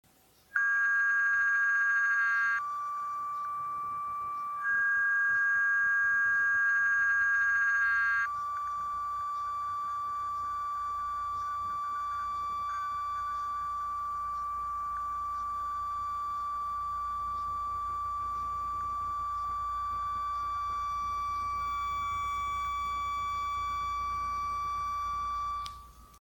А теперь треки. Записывал сотиком, около пьезика.
Уменьшил накопительный конденсатор до 220 нФ и подключил другой пьезик, даже какой-то двухчастотный сигнал появляется.